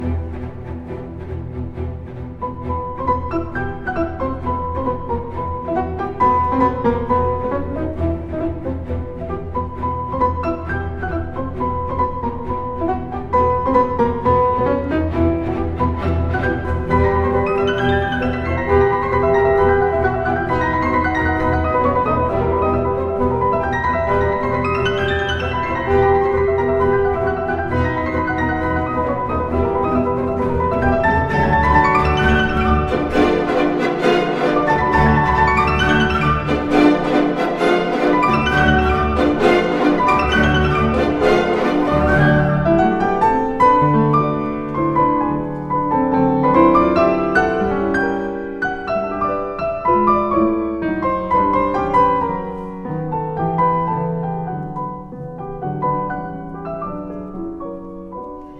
TCHAIKOVSKI, concerto pour piano n2, 03 allegro con fuoco-2.mp3